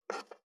563肉切りナイフ,
効果音